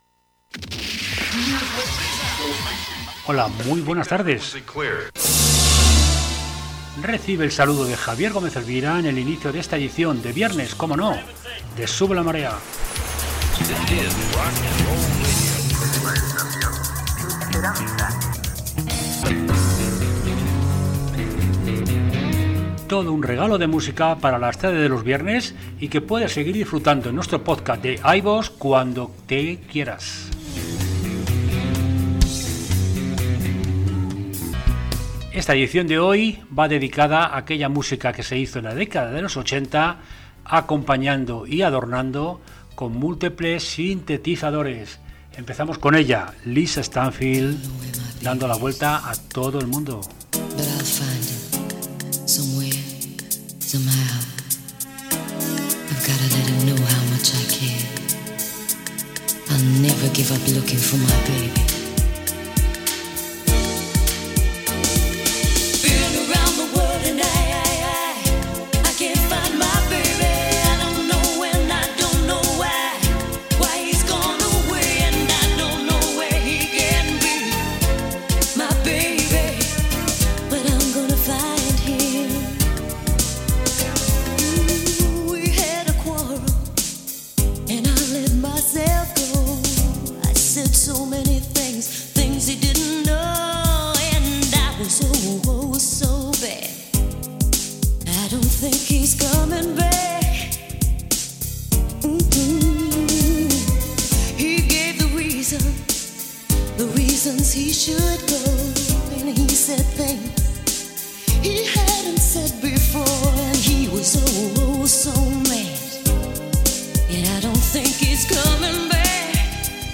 Una explosión de nuevos sonido y nuevas formas de hacer música inundaron la música que se hacia desde los primeros años 80. Desde el Moog hasta el Yamaha CS-80.